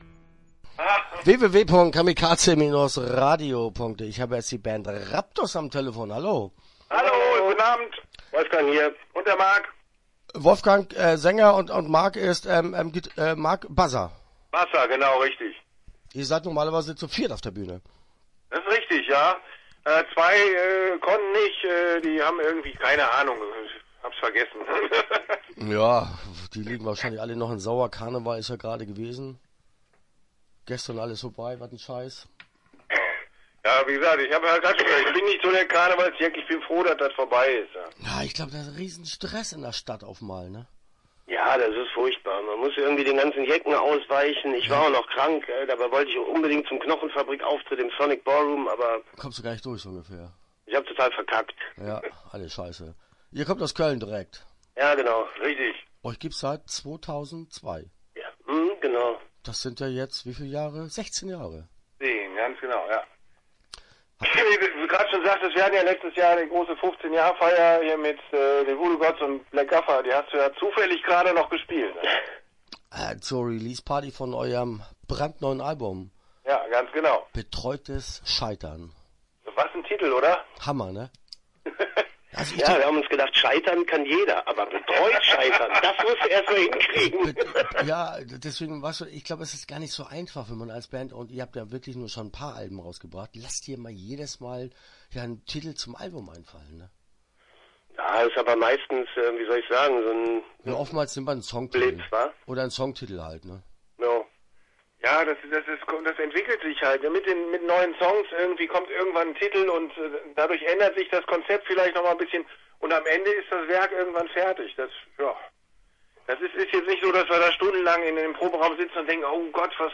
Start » Interviews » Raptus